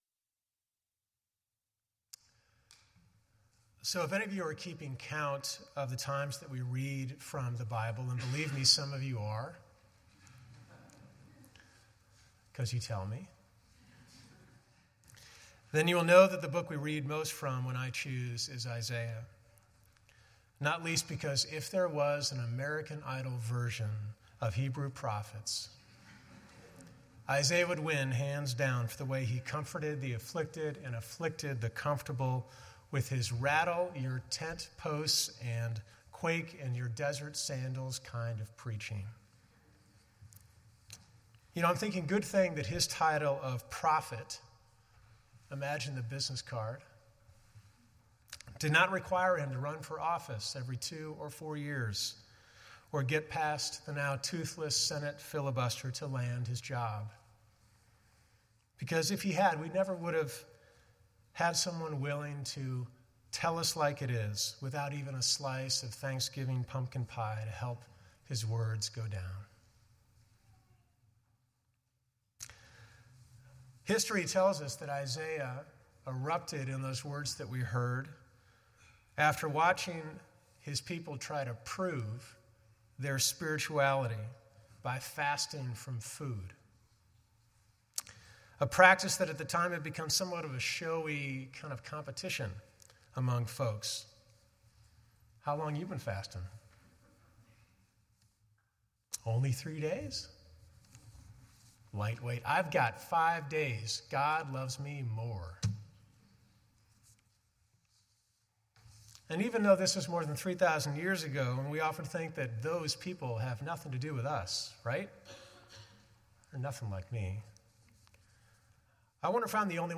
From Series: "2013 Sermons"